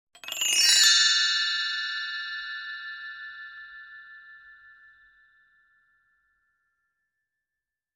game-over.mp3